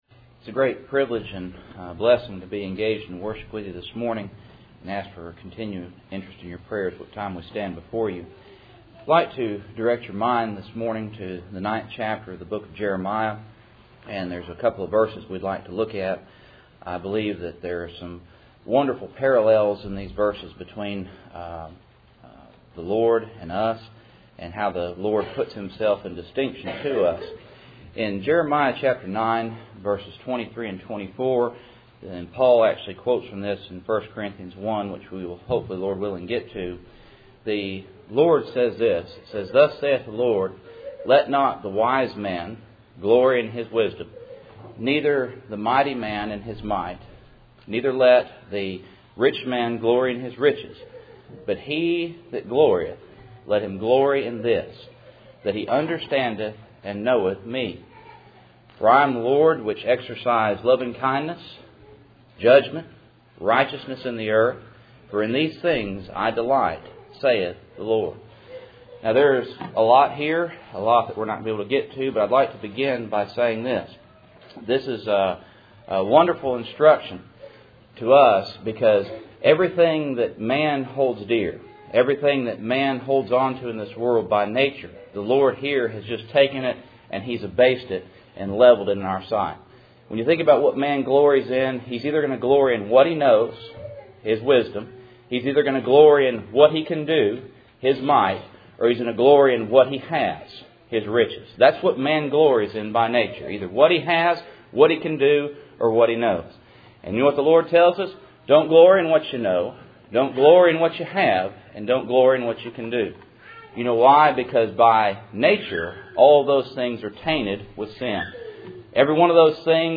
Jeremiah 9:23-24 Service Type: Cool Springs PBC Sunday Morning %todo_render% « Power Over Death and the Grave I Peter 2:7-10